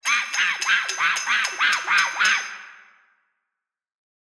Monkey toy with cymbals
Category 🗣 Voices
actor cymbals human male monkey scream toy vocal sound effect free sound royalty free Voices